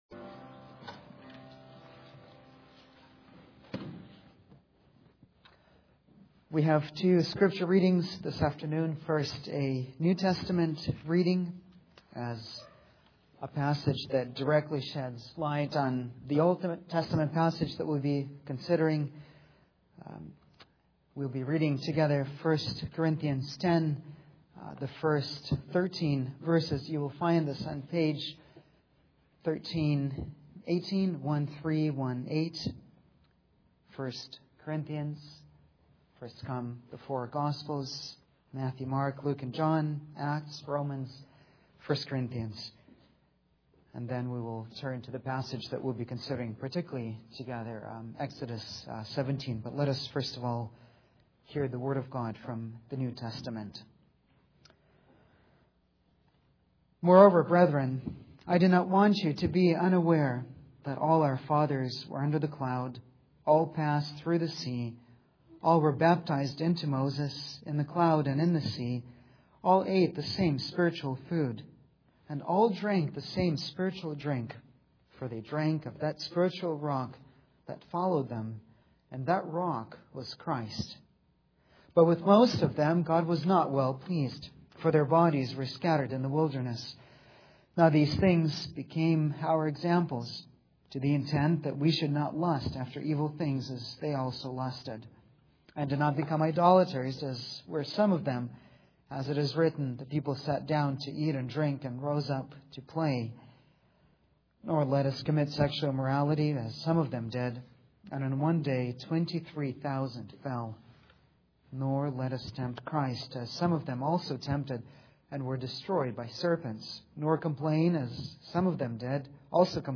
The Rock of Our Salvation | SermonAudio Broadcaster is Live View the Live Stream Share this sermon Disabled by adblocker Copy URL Copied!